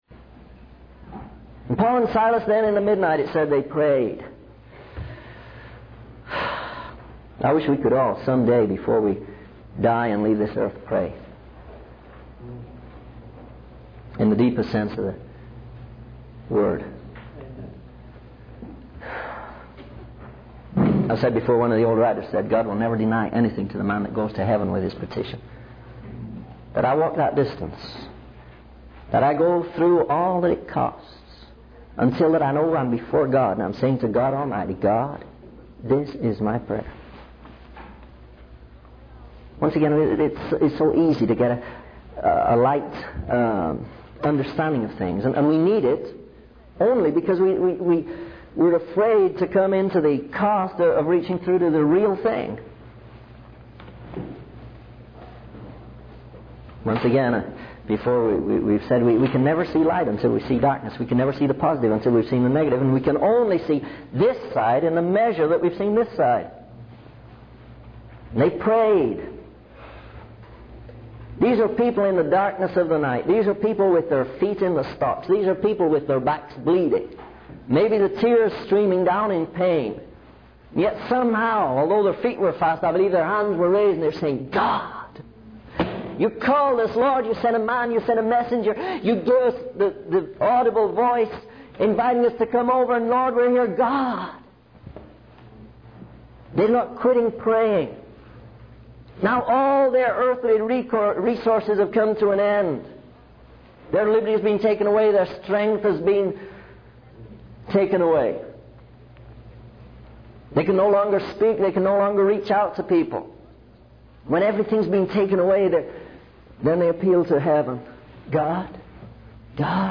The sermon explores the depth and sincerity of prayer, particularly in times of trial, emphasizing that true worship and communication with God transcend formalities.